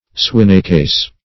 swinecase - definition of swinecase - synonyms, pronunciation, spelling from Free Dictionary Search Result for " swinecase" : The Collaborative International Dictionary of English v.0.48: Swinecase \Swine"case`\, n. A hogsty.